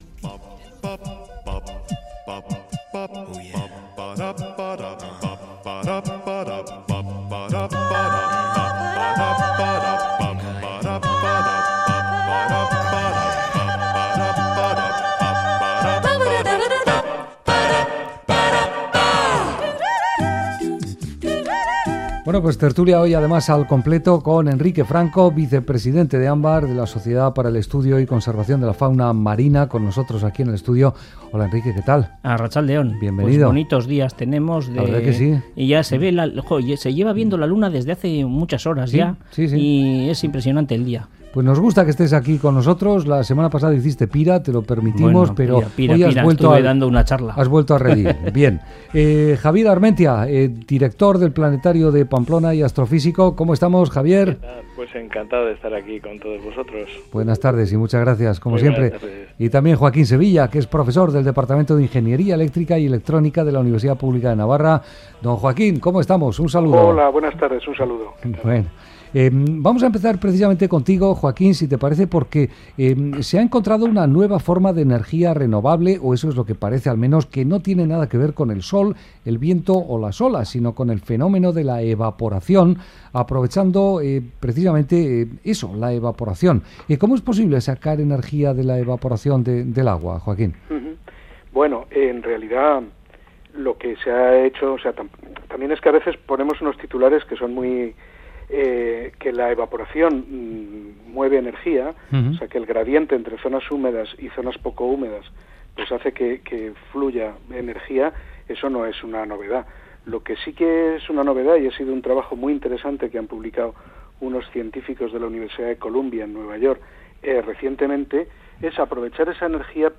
tertulia científica semanal